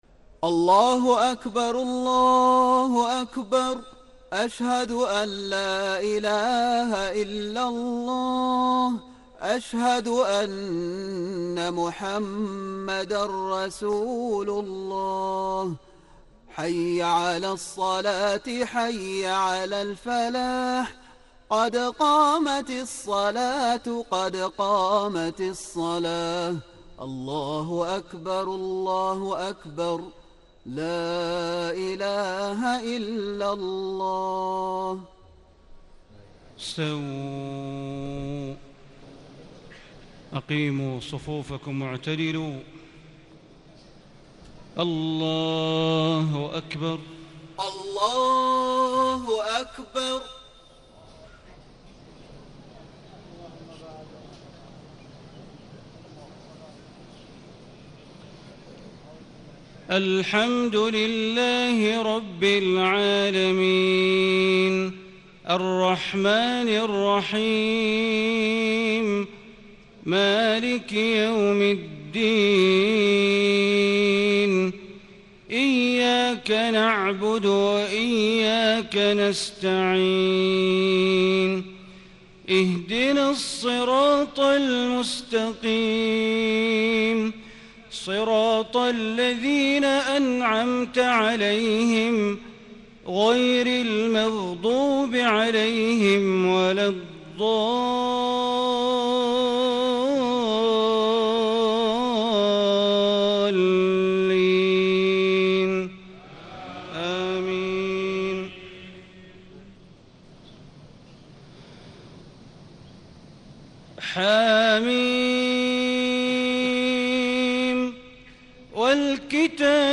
صلاة المغرب 26 رمضان 1437هـ من سورتي الدخان 1-9 و القدر > 1437 🕋 > الفروض - تلاوات الحرمين